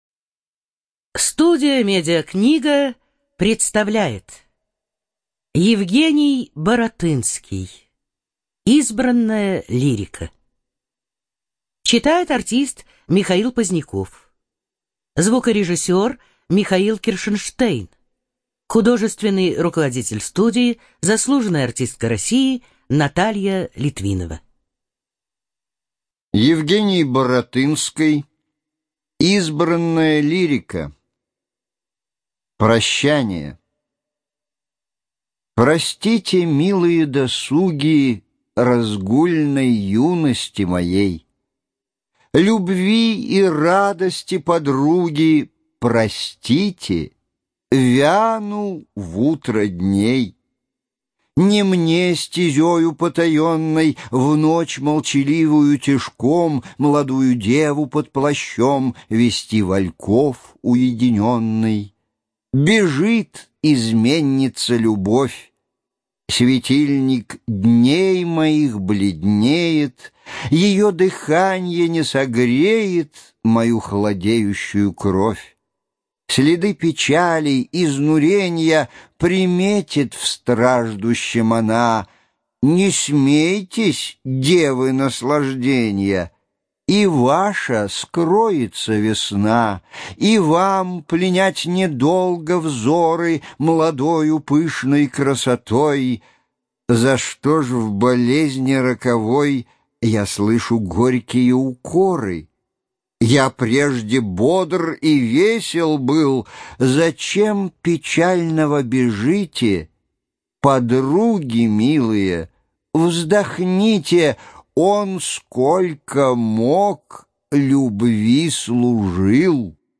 ЖанрПоэзия
Студия звукозаписиМедиакнига